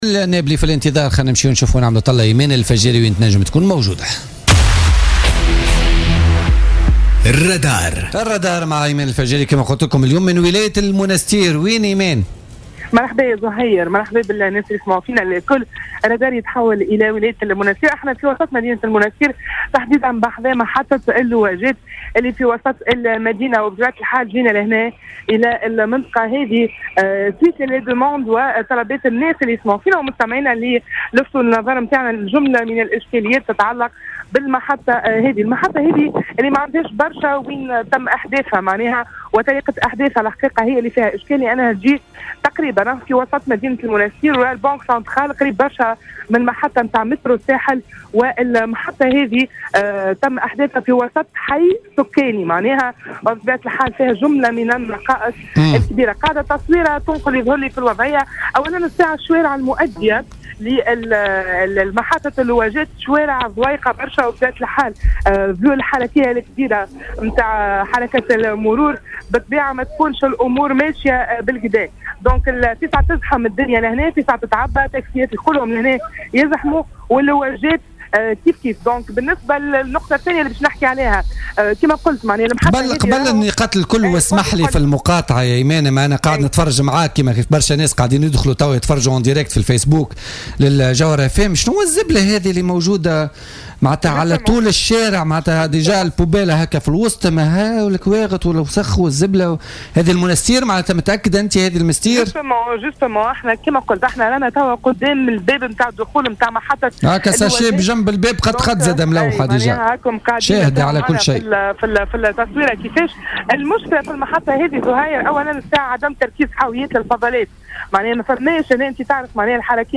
تحوّل فريق الرادار اليوم الاثنين إلى ولاية المنستير وتحديدا إلى محطة اللواجات الجديدة.